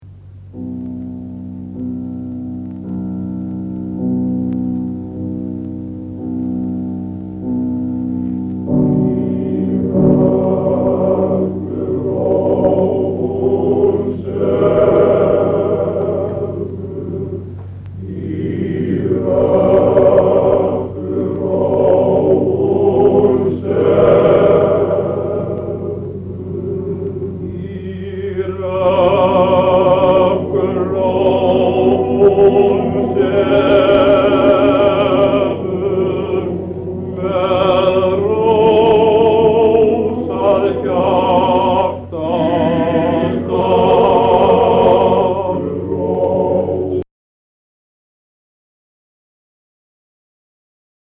Í rökkurró (karlakór með sóló - úr Strengleikum)
Kantötukór Akureyrar syngur í tóndæminu
Uppt. á æfingu.